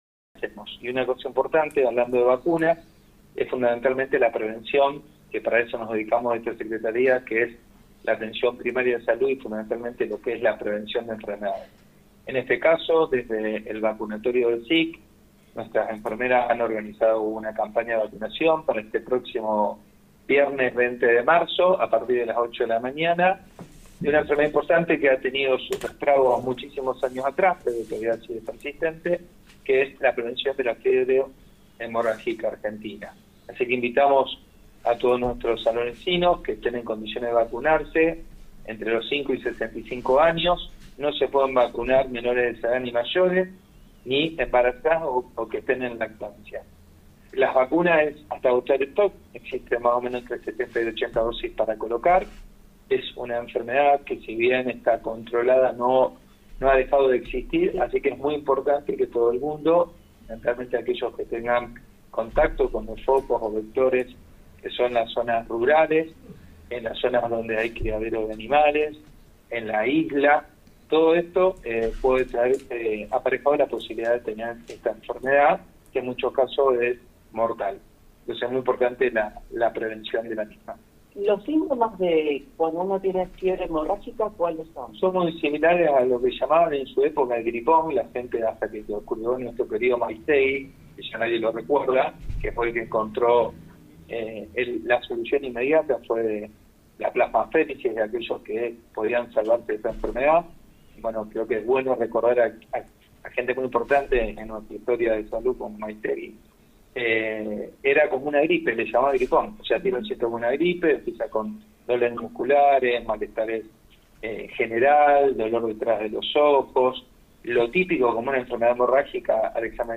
En contacto con el móvil del programa “Con Voz” de FM 102.9, el secretario de Salud, doctor Eduardo Ros, recordó la importancia de acceder a esta vacuna para prevenir la enfermedad, especialmente en zonas endémicas.